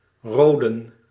Roden (Dutch pronunciation: [ˈroːdə(n)]